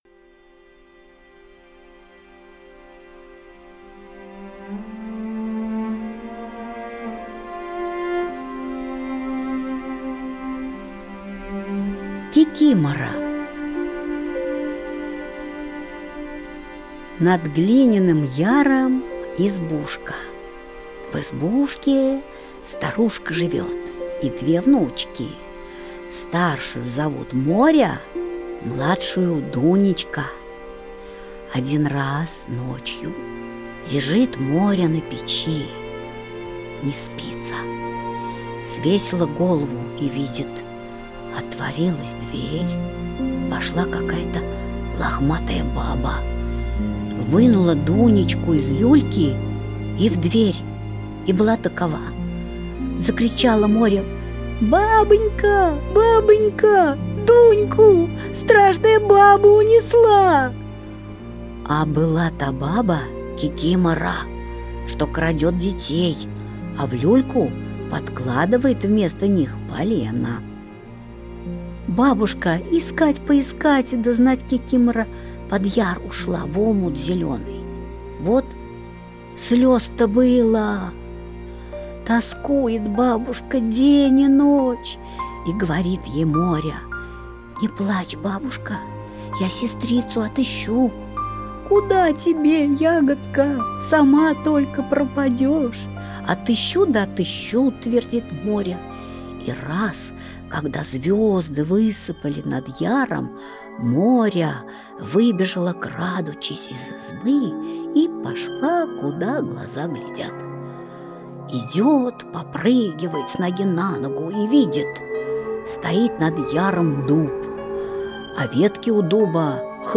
Кикимора - аудиосказка Алексея Толстого - слушать онлайн | Мишкины книжки
Кикимора – Толстой А.Н. (аудиоверсия)